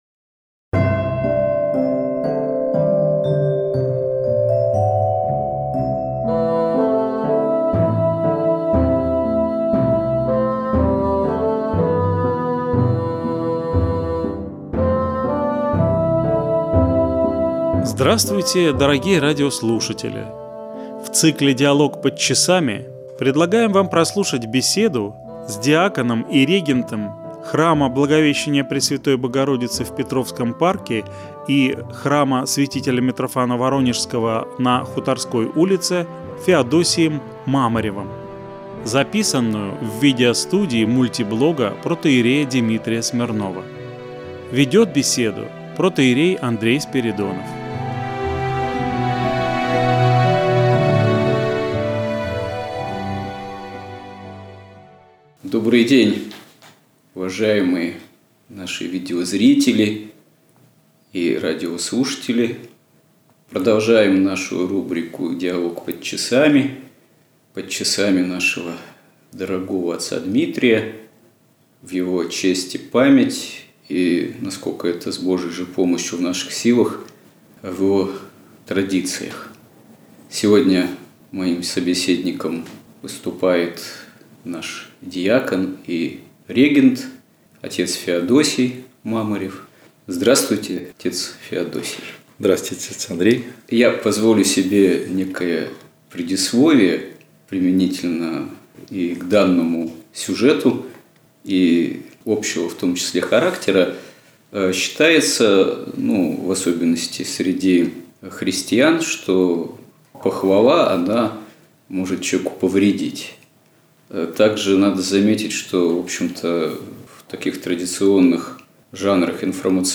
Протоиерей Димитрий Смирнов и современная музыкальная культура. Беседа